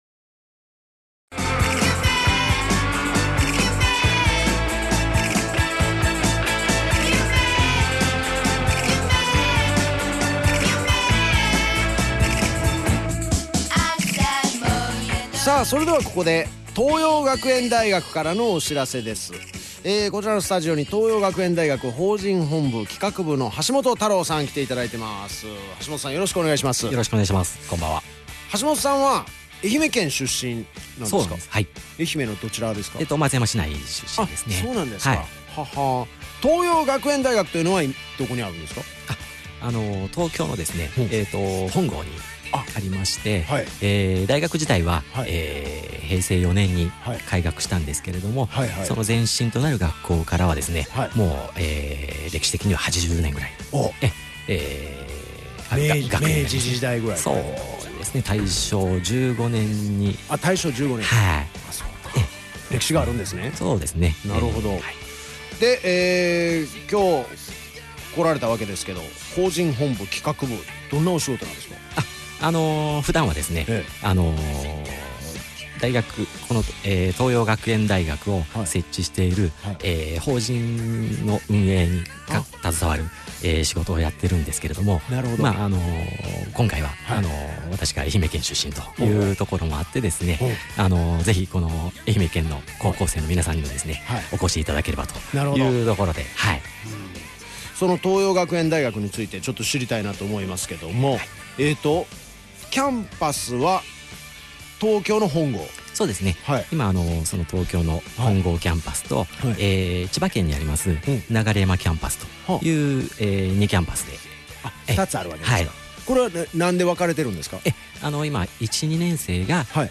愛媛県のラジオ局・ＦＭ愛媛（FM79.7MHz）で、地方入試ＰＲ。